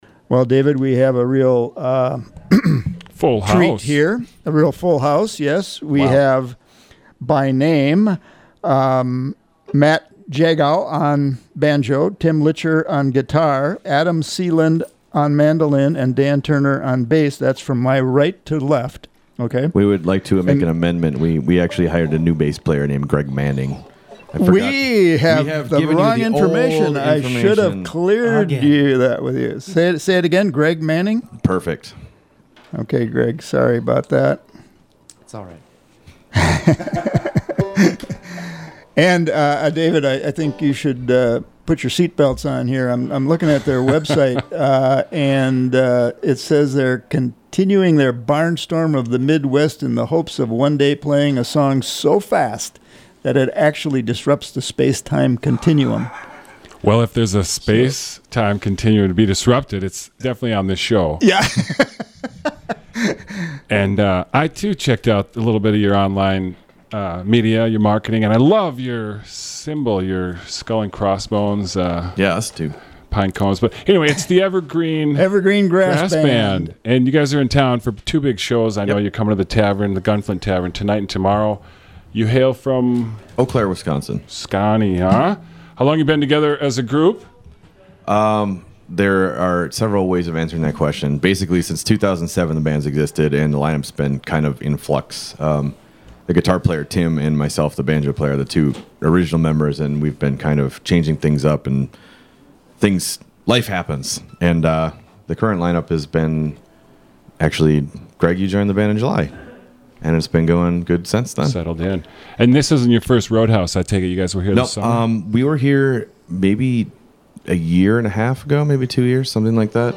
Studio A
fast bluegrass picking and great harmonies